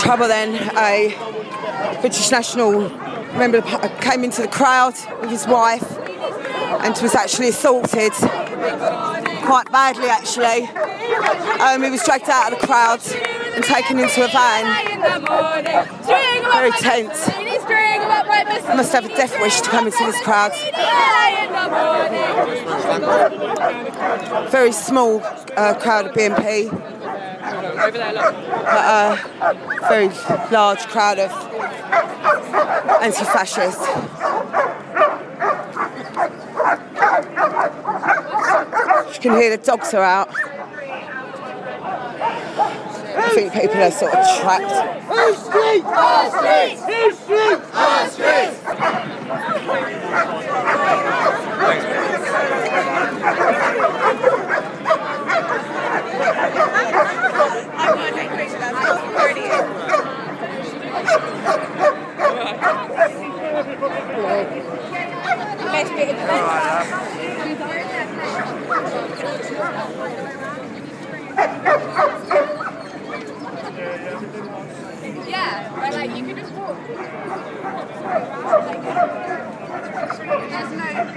Very tense